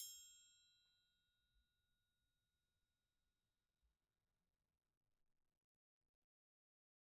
Triangle3-Hit_v1_rr2_Sum.wav